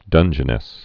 (dŭnjə-nĕs, -nĭs)